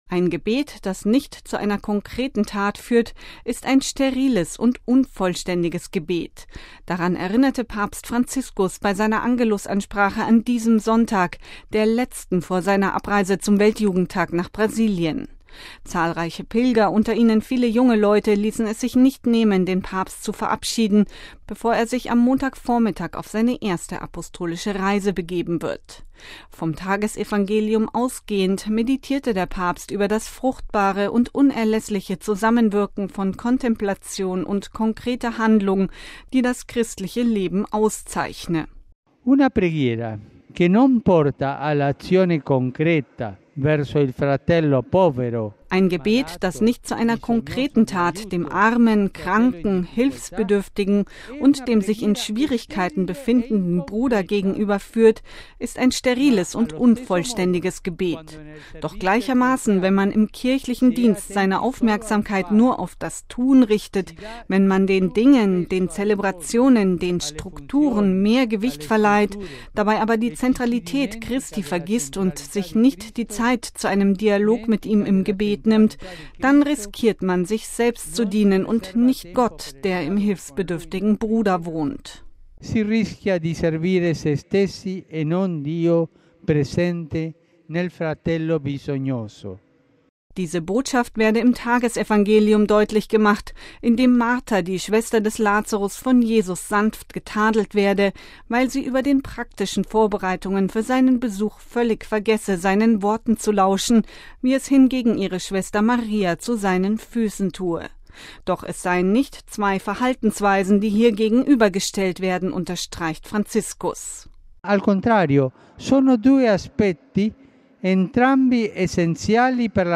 Ein Gebet, das nicht zu einer konkreten Tat führt, ist ein steriles und unvollständiges Gebet: Daran erinnerte Papst Franziskus bei seiner Angelusansprache am Sonntag, 21. Juli, der letzten vor seiner Abreise zum Weltjugendtag nach Brasilien. Zahlreiche Pilger, unter ihnen viele junge Leute, ließen es sich nicht nehmen, den Papst zu verabschieden, bevor er sich am Montagvormittag auf seine erste Apostolische Reise begab.